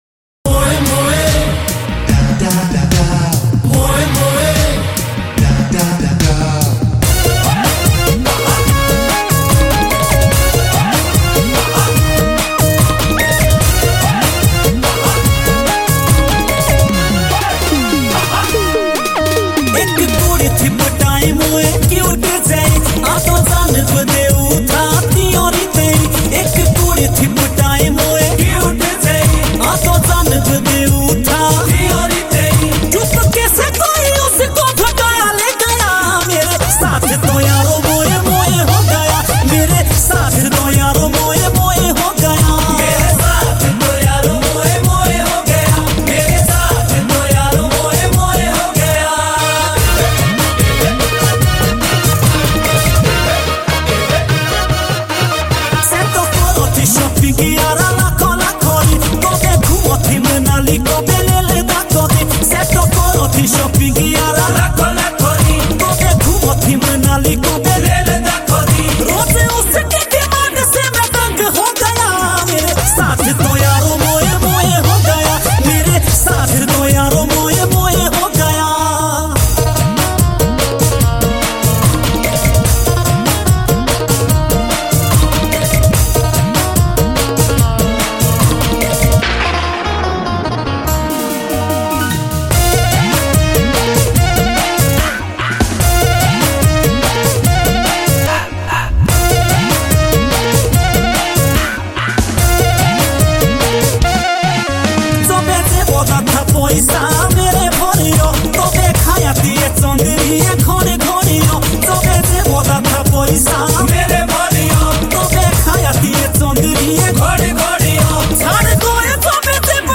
Himachali Songs